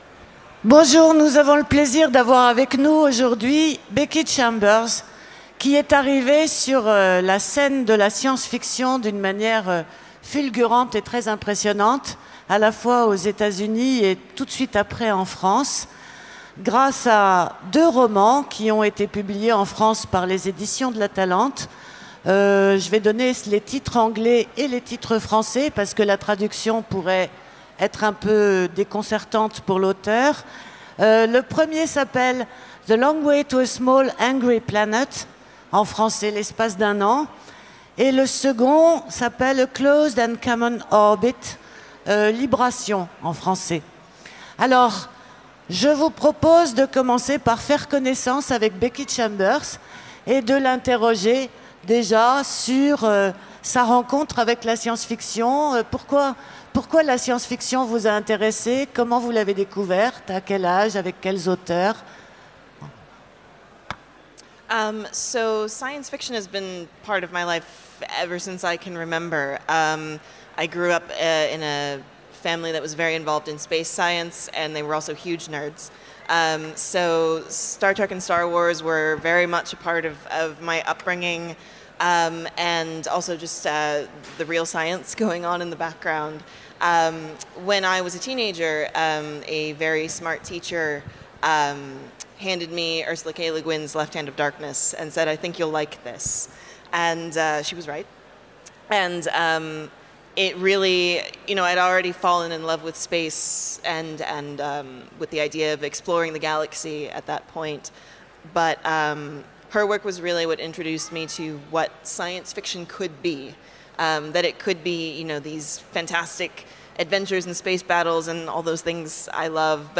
- le 15/11/2017 Partager Commenter Utopiales 2017 : Rencontre avec Becky Chambers Télécharger le MP3 à lire aussi Becky Chambers Genres / Mots-clés Rencontre avec un auteur Conférence Partager cet article